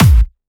VEC3 Clubby Kicks
VEC3 Bassdrums Clubby 034.wav